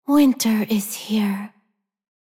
大厅语音